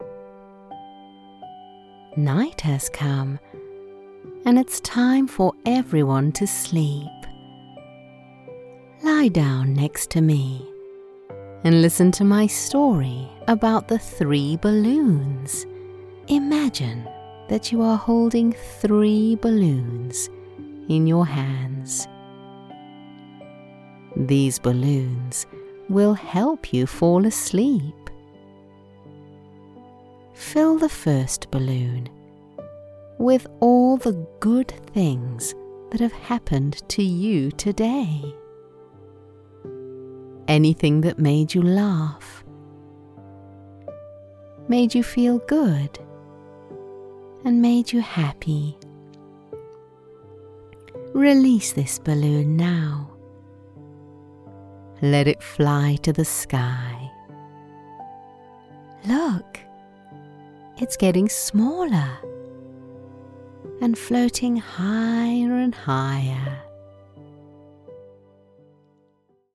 Erzählung
Meine Stimme ist natürlich und freundlich, aber auch vollmundig und bestimmend.
Audio Technica AT2020 Mikrofon